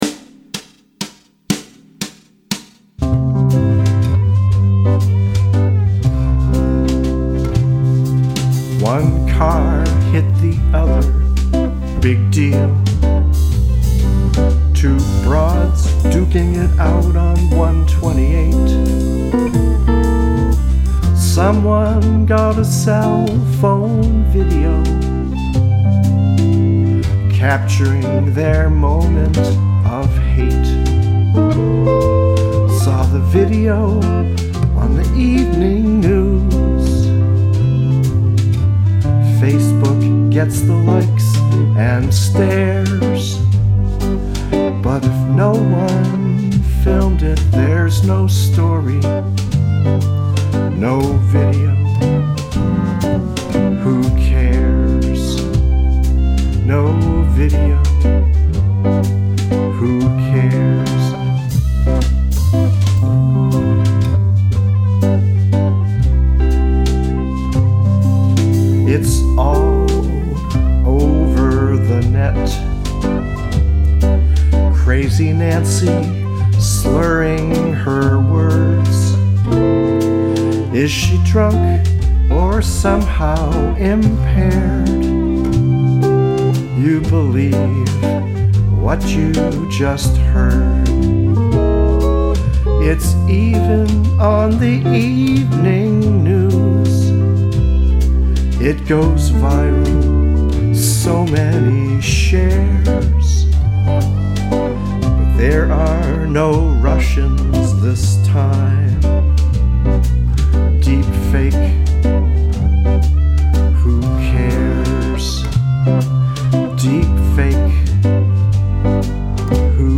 demo recording.